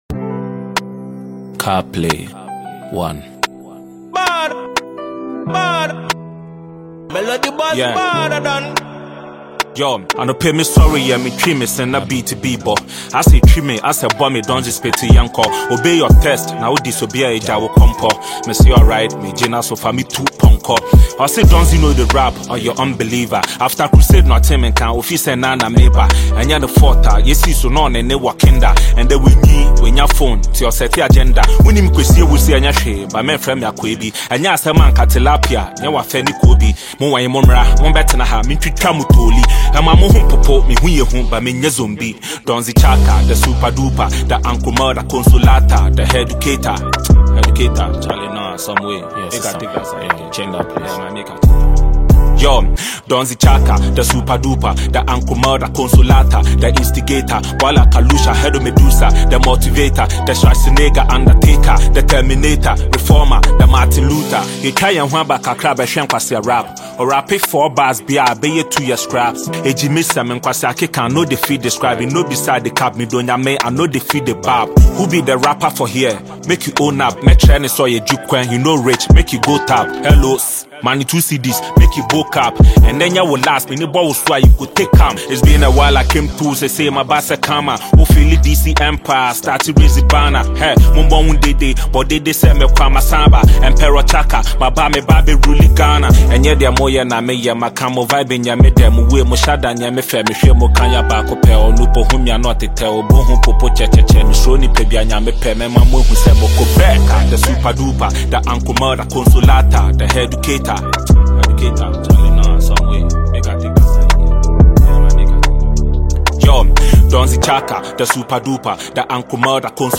Ghana Music
is a mid-tempo, smooth Hip-Hop record